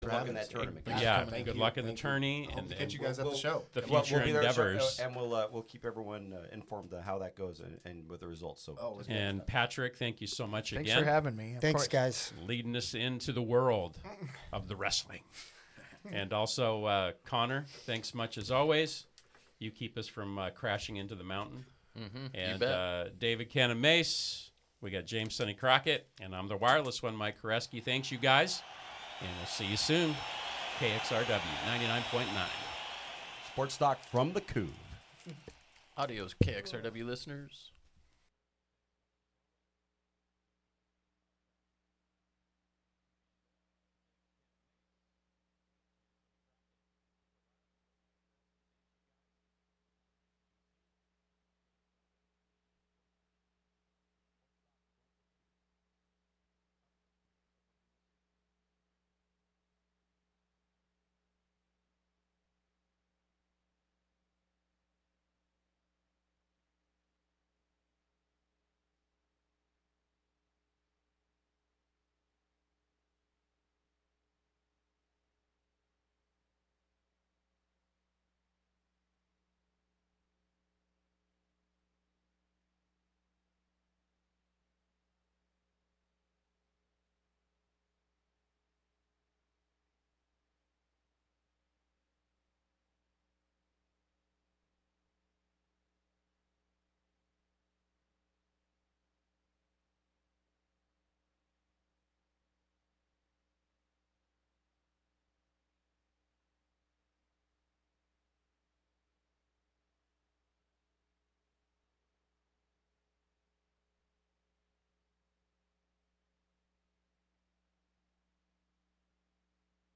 A mix of independent & vintage alt, outlaw, cosmic, gothic, red dirt & honky tonk country, roots, blue grass, folk, americana, rock and roll & even desert psych and stadium hits (when acceptable). We feature a strong emphasis on female artists.
And we get in the weeds with artist interviews & field recordings, new releases & story telling—on every Thursday 2-3pm.